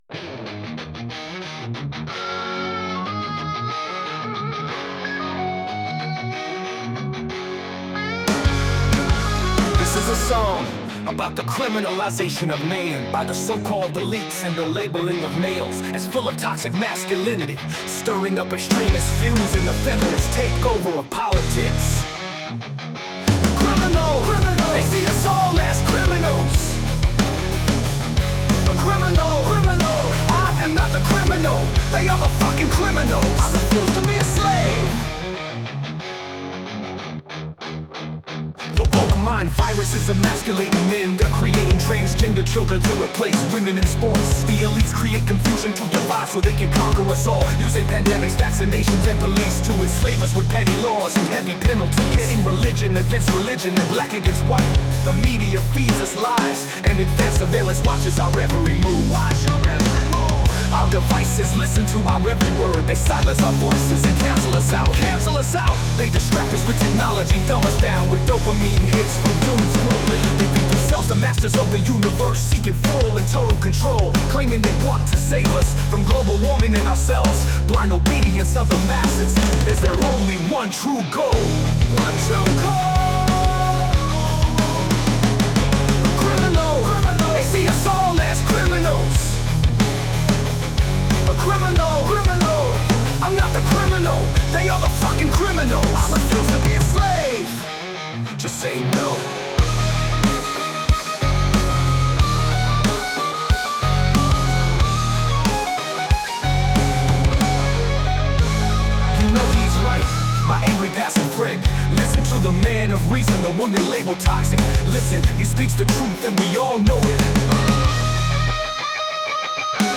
Criminal - Blues - harmonica 01.mp3